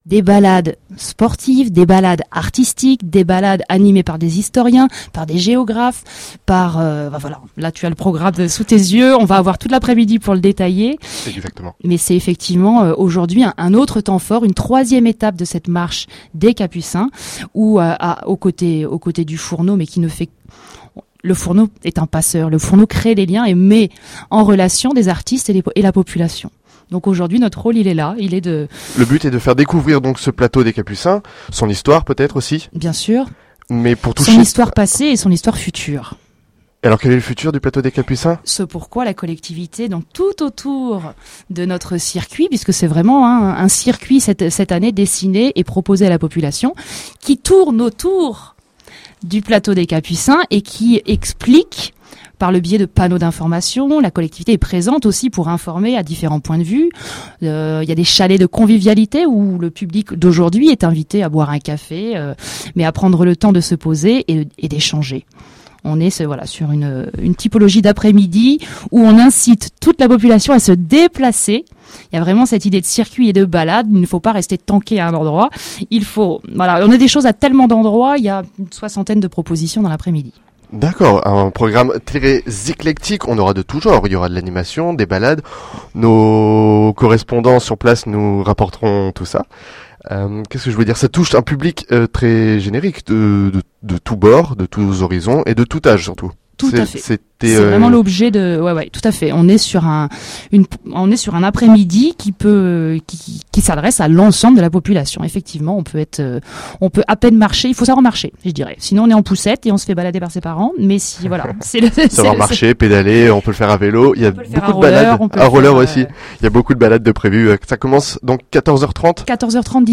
L'émission " Radio Capucins" diffusée le dimanche 26 octobre 2014 en direct du Centre Social de Kerangoff est le fruit d'un partenariat entre Le Centre national des arts de la rue Le Fourneau et…